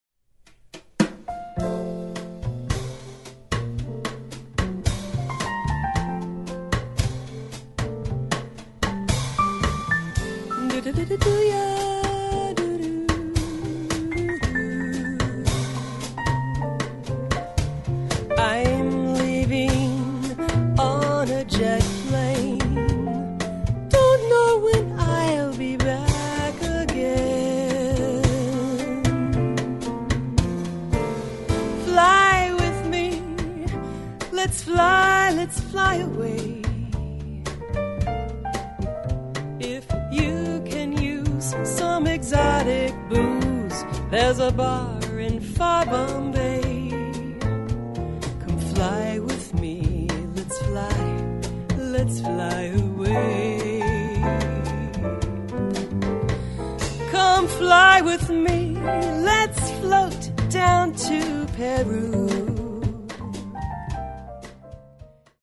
A vibrant vocalist with a love of both jazz and modern music
rich and expressive vocals
jazz vocalist